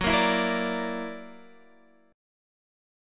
F#+7 Chord